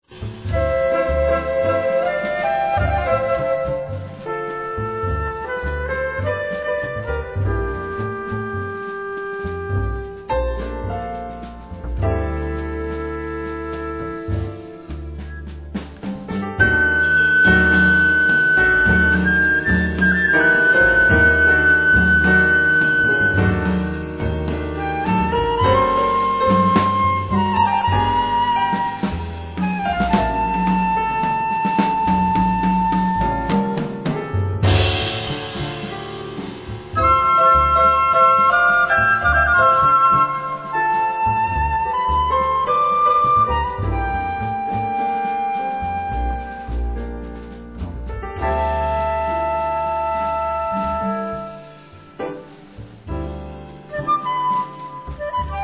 Saxphone,Flute
Piano
Drums
Double bass